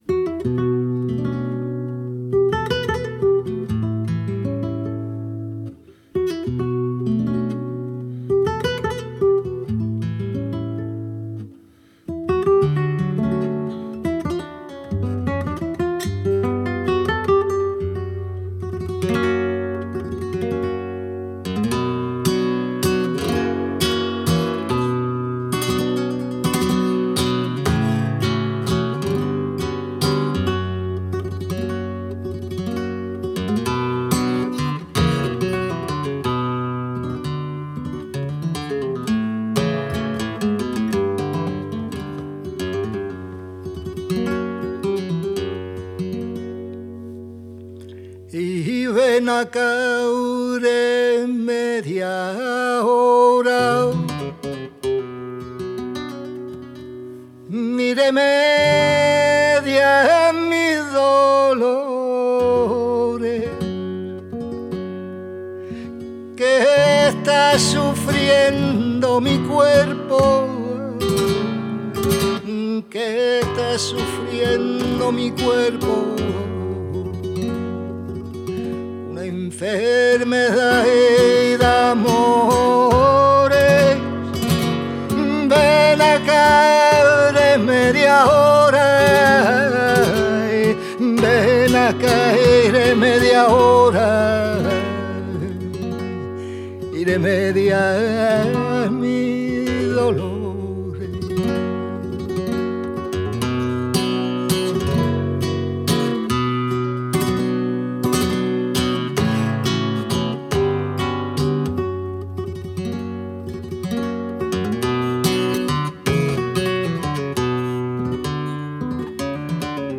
Decazeville, Zone du Centre, au Laminoir.
Le répertoire de la Barraca del Sur mêle le Flamenco Andalou aux traditions musicales d'Amérique Latine...Tangos, milongas, habaneras, zambas, merengue, joropos, guajiras, valses créoles et chants des Andes mais aussi des chansons de Victor Jara, Atahualpa Yupanqui, Paco Ibañez, Maria Dolores Pradera, Carlos Gardel, Alfredo Zitarrosa, Carlos Cano, Chabuca Granda, Violeta Parra et d'autres encore, trop peu connu de ce coté-ci de l'Atlantique et des Pyrénées.
La petenera